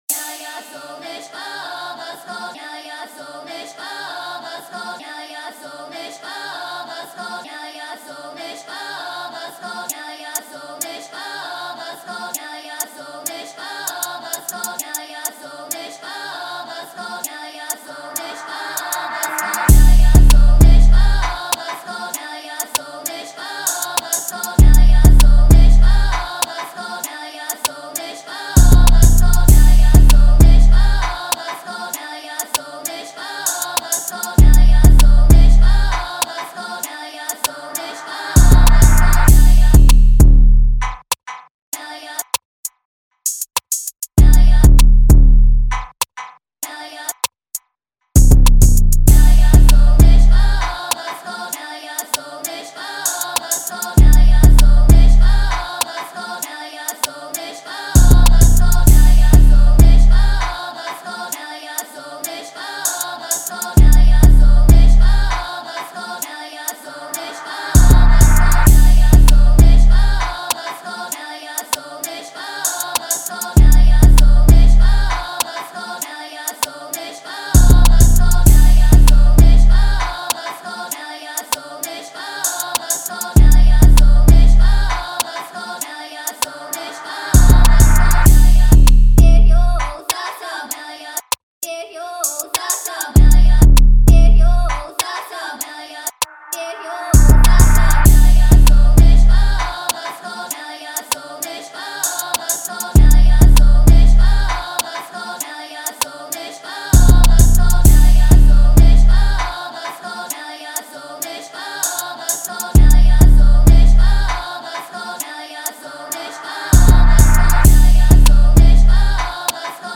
2025 in Official Instrumentals , Rap Instrumentals